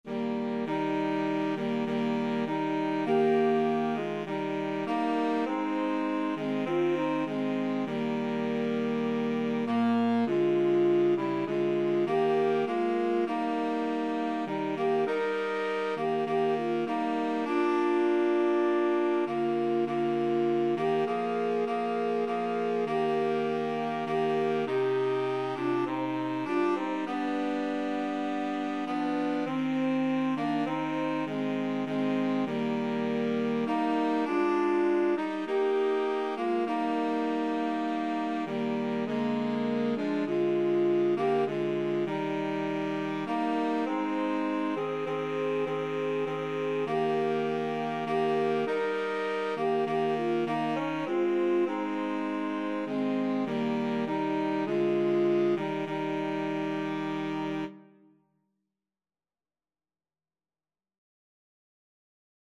Alto SaxophoneTenor SaxophoneBaritone Saxophone
4/4 (View more 4/4 Music)
Classical (View more Classical Woodwind Trio Music)